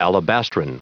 Prononciation du mot alabastrine en anglais (fichier audio)
Prononciation du mot : alabastrine